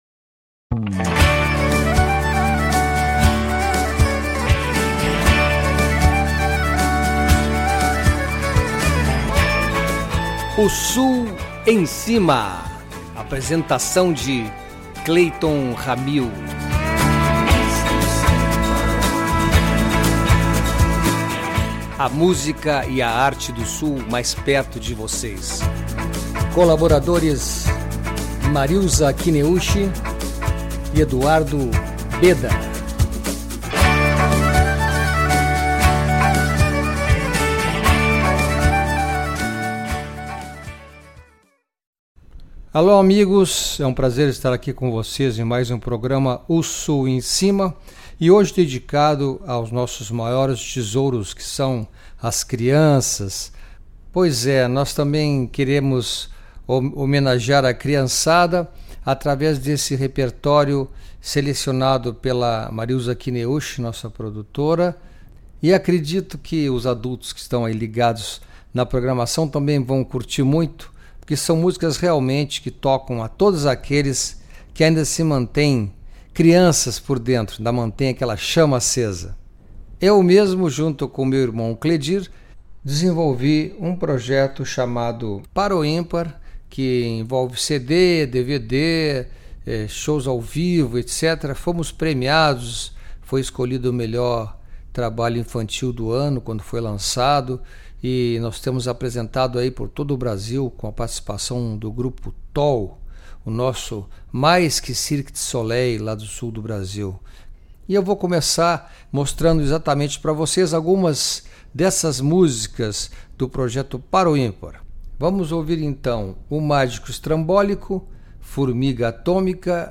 Selecionamos um repertório com músicas de grandes artistas!